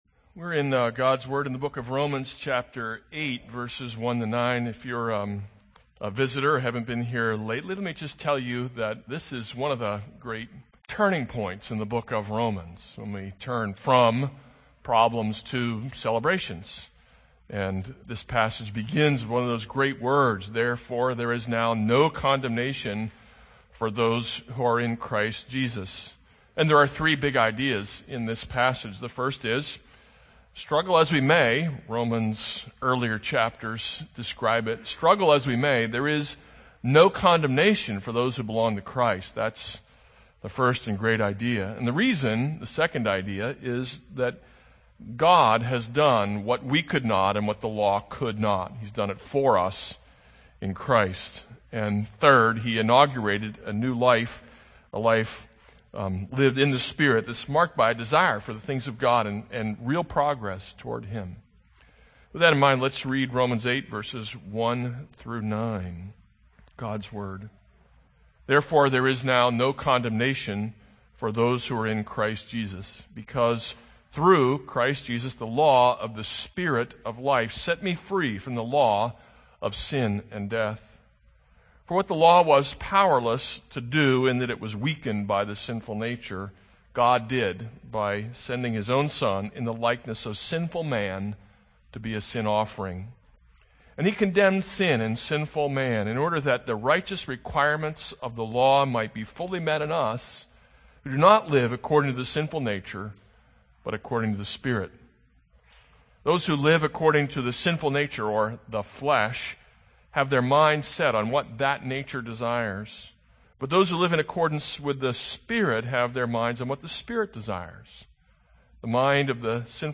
This is a sermon on Romans 8:1-9.